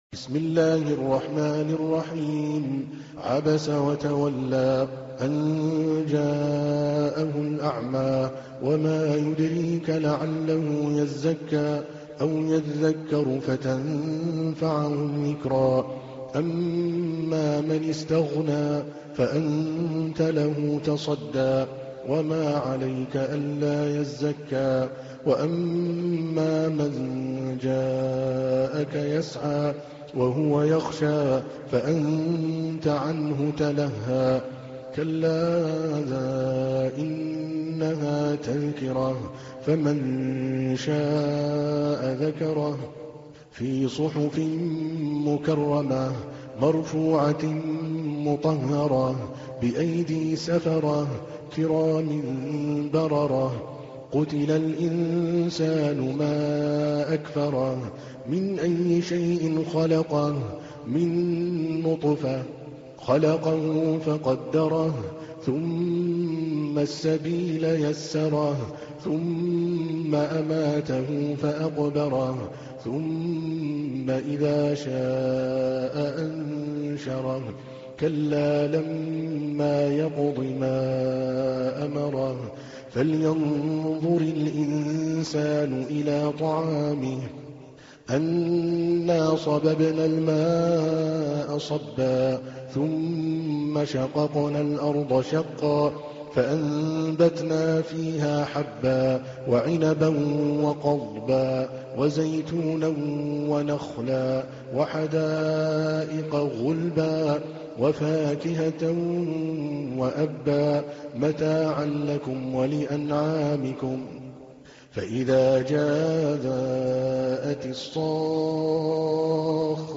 تحميل : 80. سورة عبس / القارئ عادل الكلباني / القرآن الكريم / موقع يا حسين